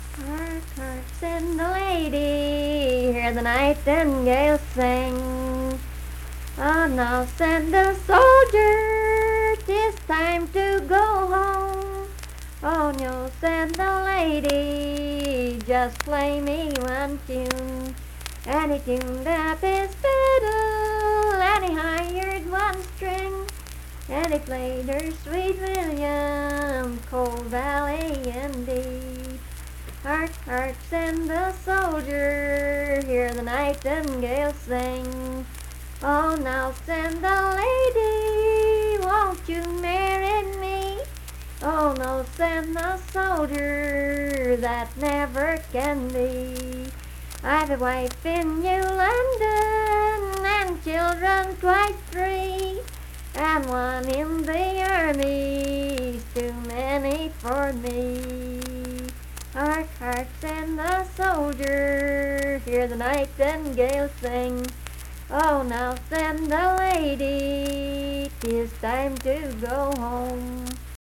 Unaccompanied vocal music
Performed in Strange Creek, Braxton, WV.
Voice (sung)